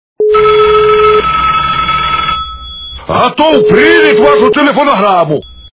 » Звуки » Смешные » Голос с сельским акцентом - Готов принять Вашу телефонограмму
При прослушивании Голос с сельским акцентом - Готов принять Вашу телефонограмму качество понижено и присутствуют гудки.
Звук Голос с сельским акцентом - Готов принять Вашу телефонограмму